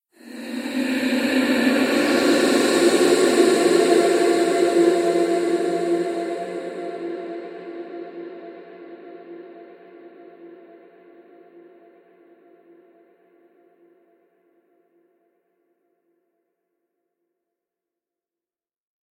Женский призрак зовет
zhenskij_prizrak_zovet_fdb.mp3